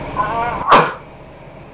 Sneezing Sound Effect
Download a high-quality sneezing sound effect.
sneezing-2.mp3